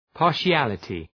{pɑ:r’ʃælətı}